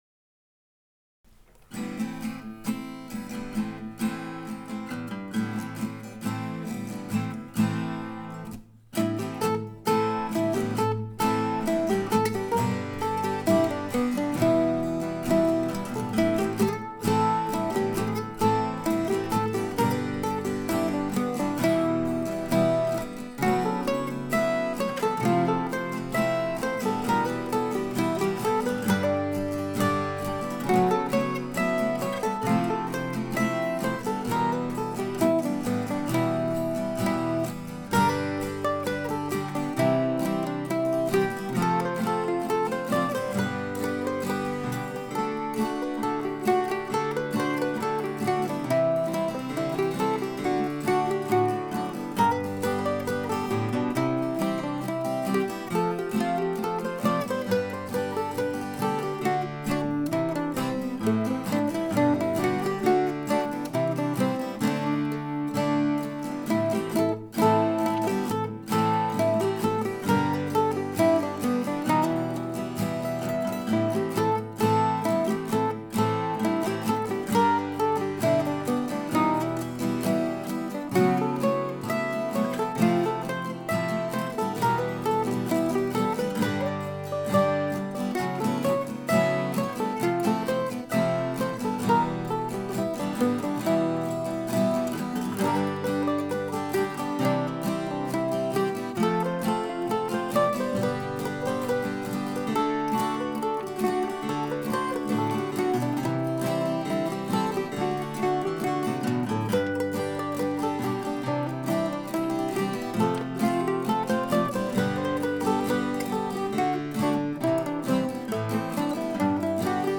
I recorded "Yew Sunday" back in the spring in a video but here I am finally getting around to re-recording it with guitar backup. In fact, just for fun, I used two guitars playing basically the same thing.